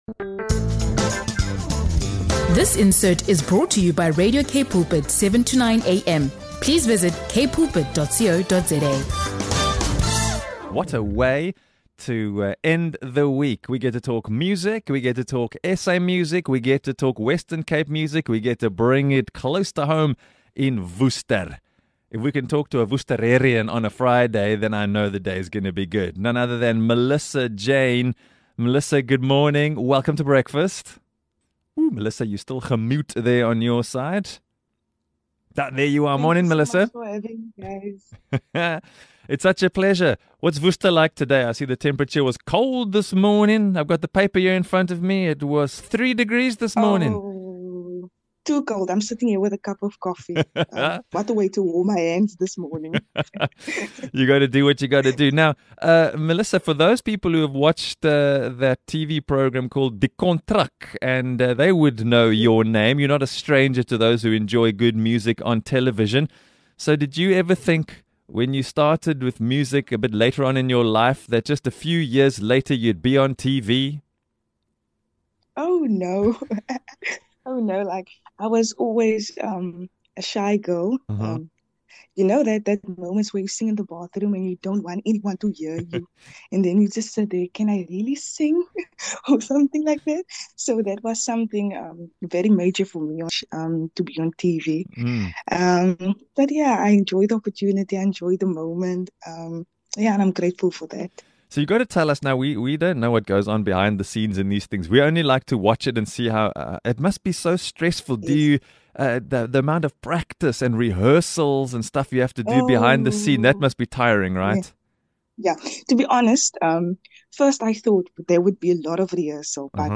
In this inspiring interview